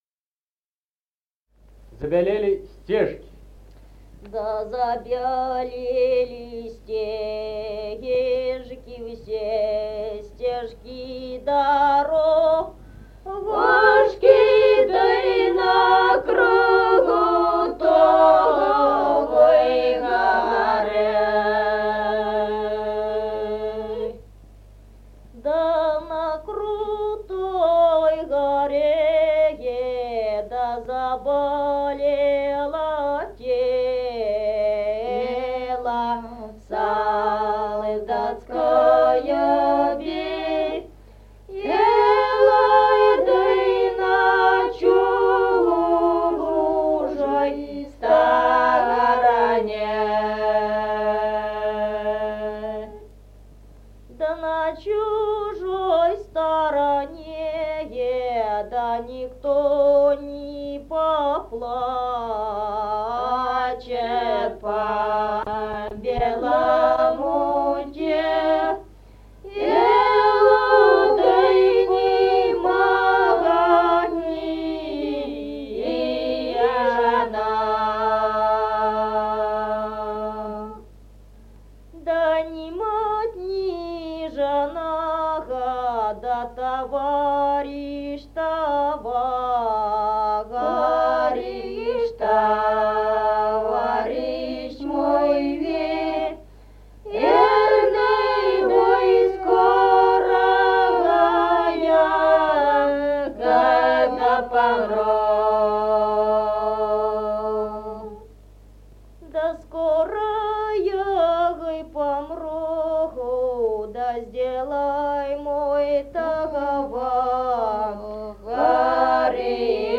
Музыкальный фольклор села Мишковка «Да забелели стежки», воинская.